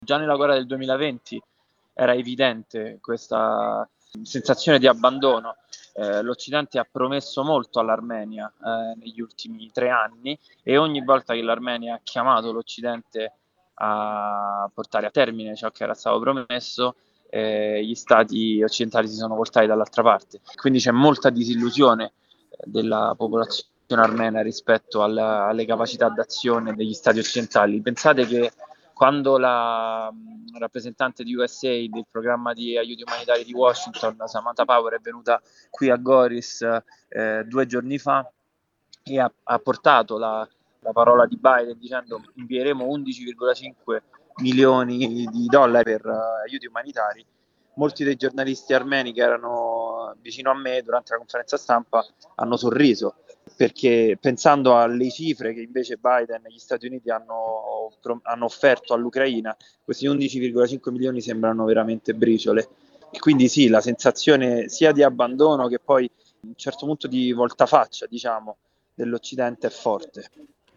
che si trova al confine tra Armenia e Azerbaijan.